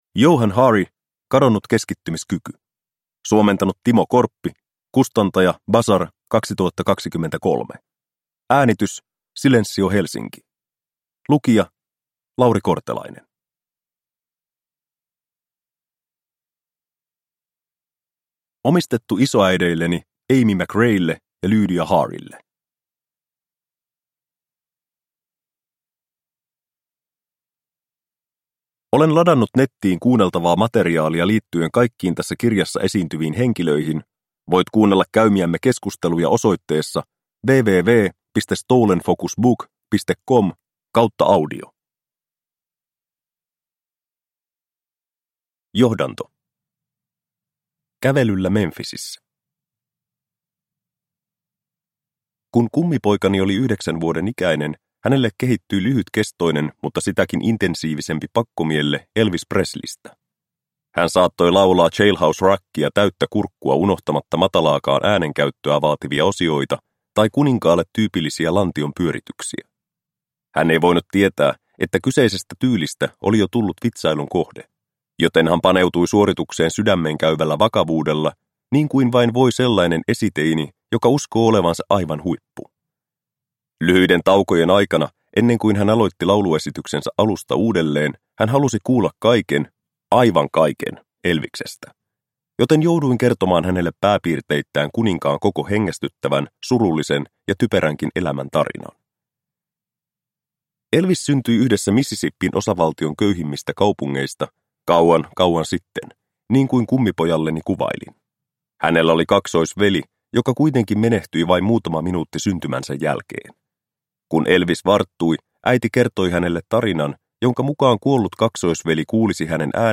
Kadonnut keskittymiskyky – Ljudbok – Laddas ner